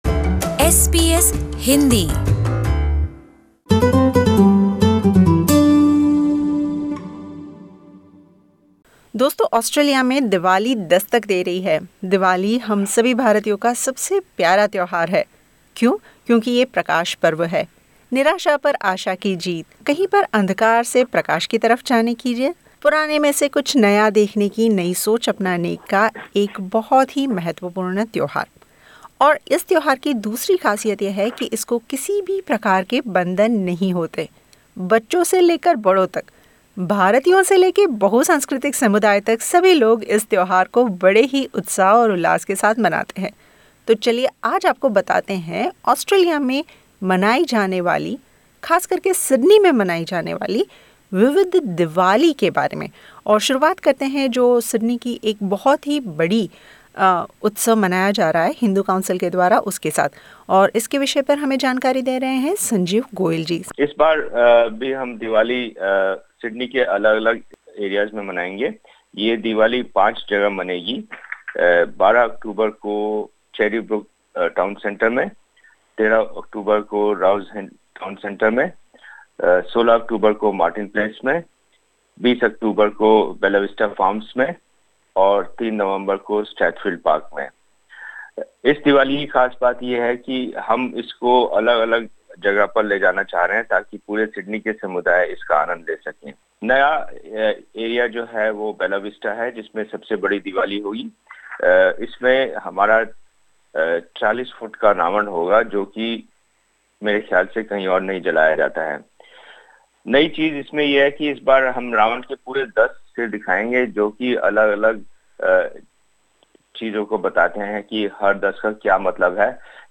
In an interview with SBS Hindi